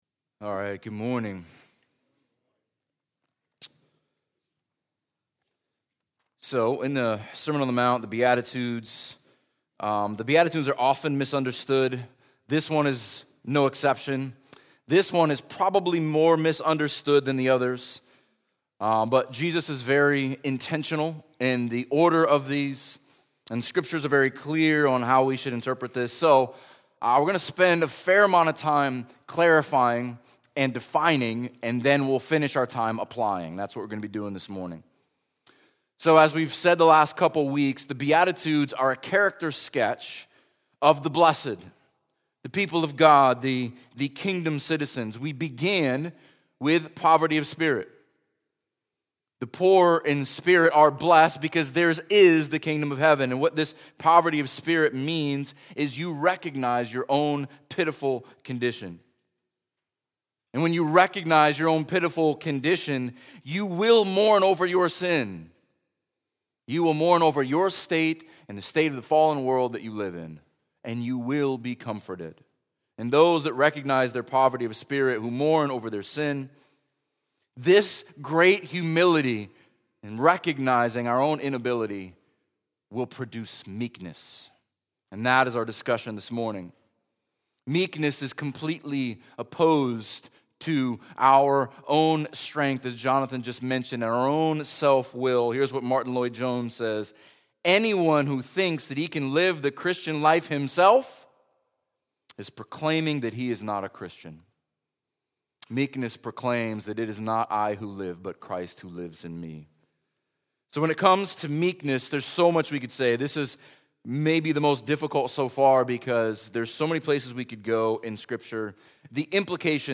Grace Fellowship Sanford Sermons